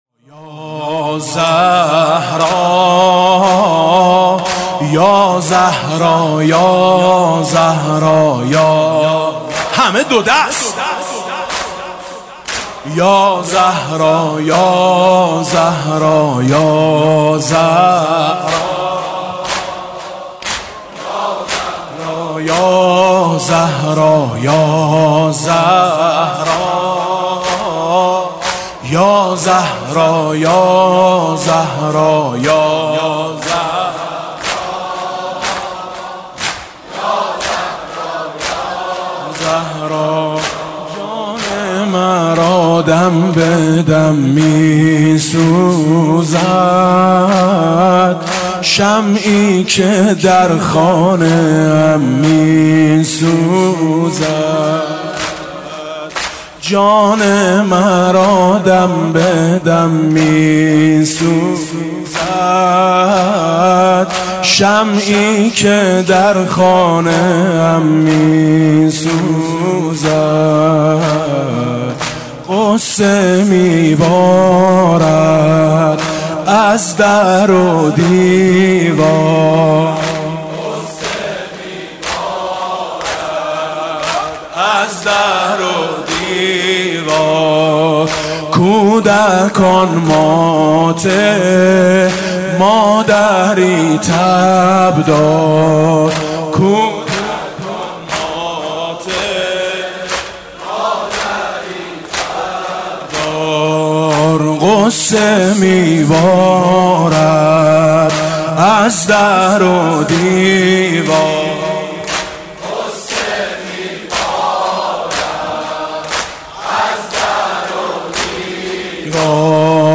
28 بهمن 96 - مسجد علی بن موسی الرضا - زمینه - دلگیر است و گریه آور خانه بی لبخند مادر